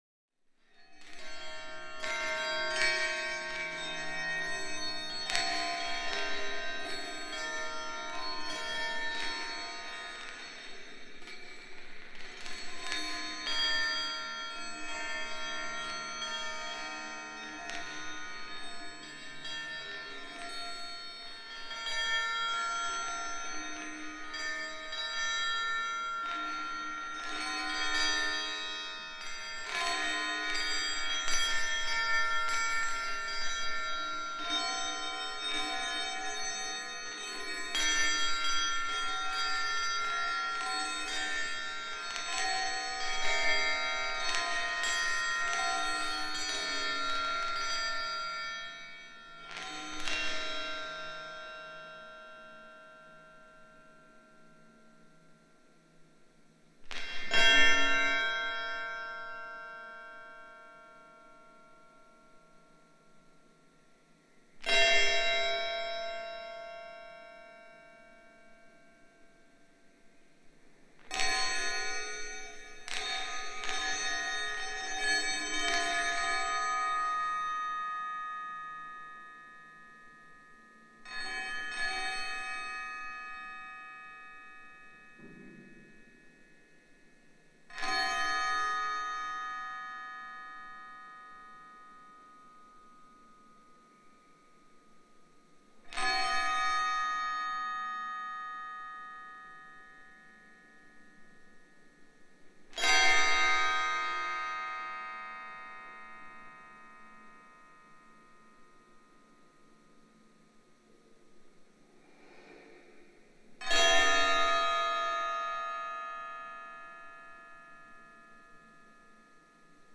Chinese-Ball-Chime
ball chime chimes clank clonk ding ting wind sound effect free sound royalty free Nature